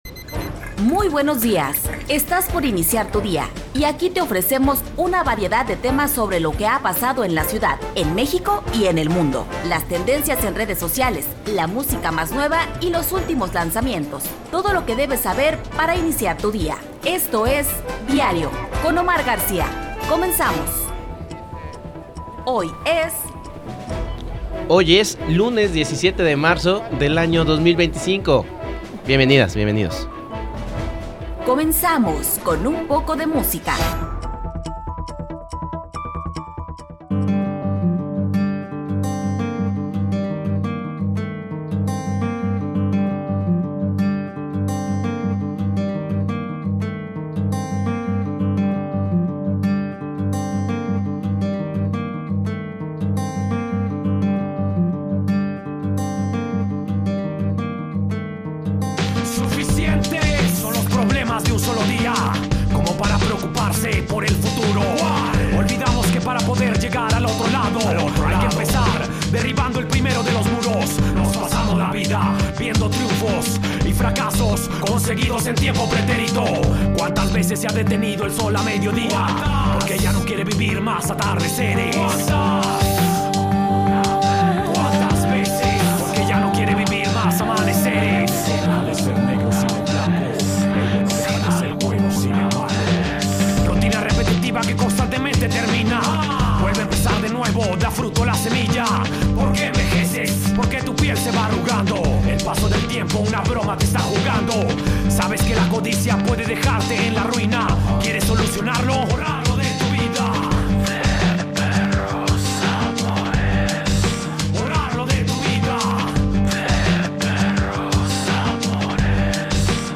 Hoy en Diario la charla es con el productor musical Gustavo Santaolalla.